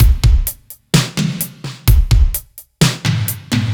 Index of /musicradar/french-house-chillout-samples/128bpm/Beats
FHC_BeatC_128-02.wav